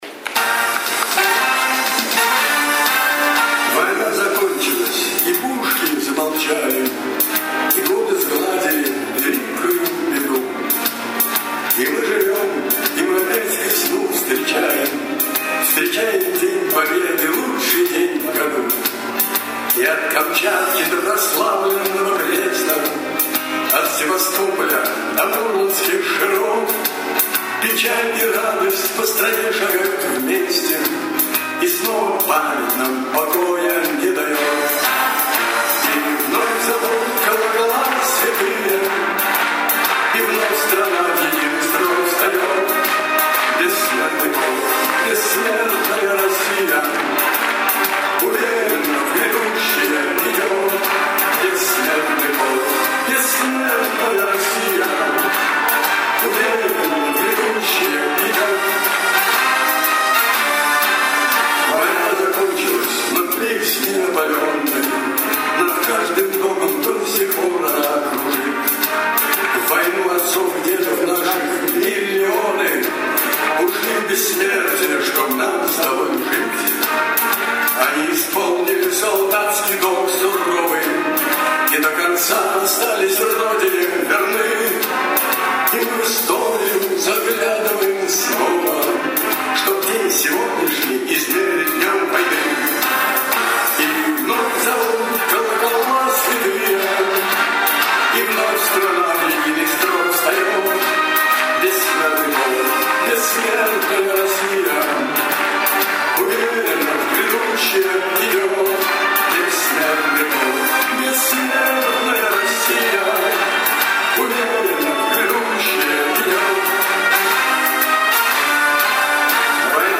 в конце своего выступления исполнил марш